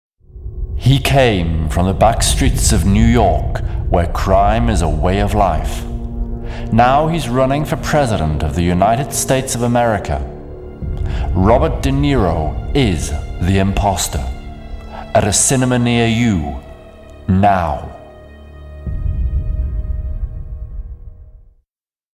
voix anglaise, comédien pro britannique, beaucoup d'expérience de voix off
britisch
Sprechprobe: Sonstiges (Muttersprache):
British actor with lots of experience of voice work and theatre